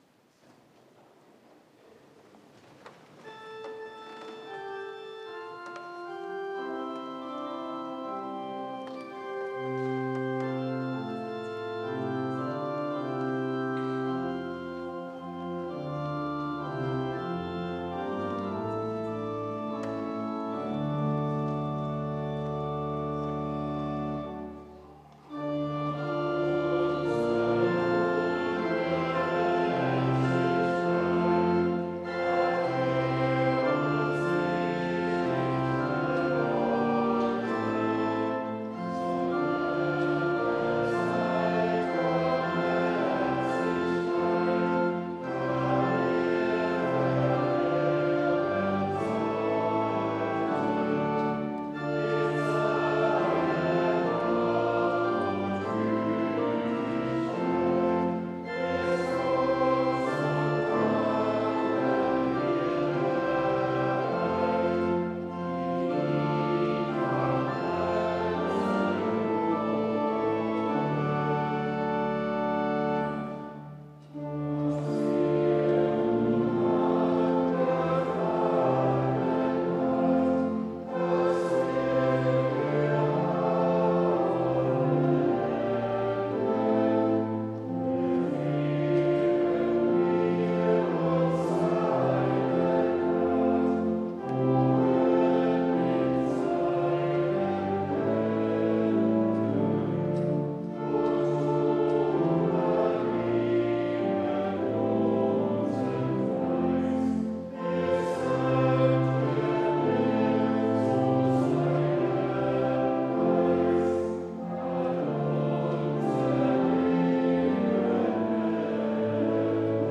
O gläubig Herz, Gott dankbar sei... (LG 388,6-9) Evangelisch-Lutherische St. Johannesgemeinde Zwickau-Planitz
Audiomitschnitt unseres Gottesdienstes am 8. Sonntag nach Trinitatis 2023